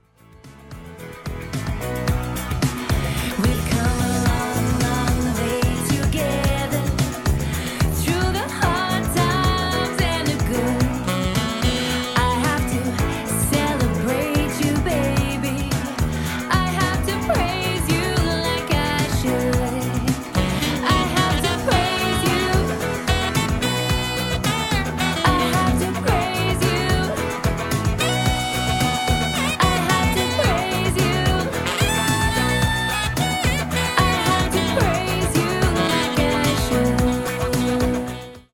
Vocals | Saxophone | Percussion | DJ | MC